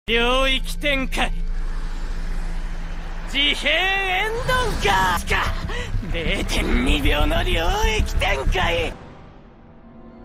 mahito domain expansion Meme Sound Effect
This sound is perfect for adding humor, surprise, or dramatic timing to your content.